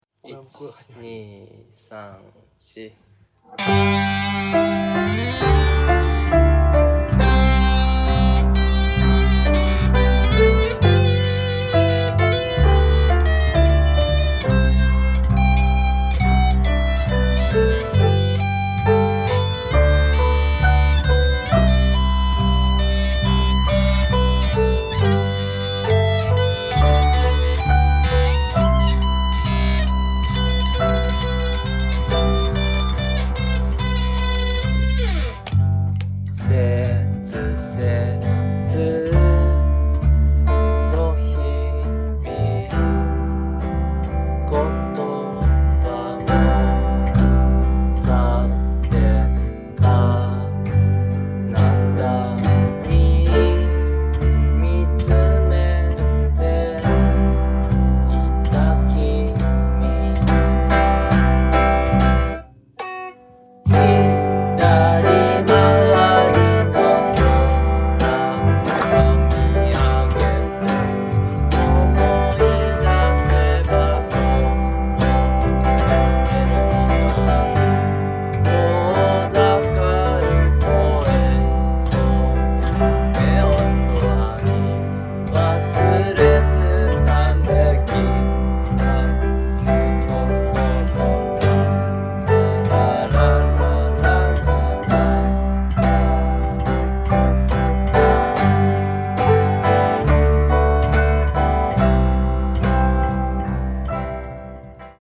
サイケデリックなサウンドと叙情性が発露されていく歌が◎！
ギターも凄くイイです！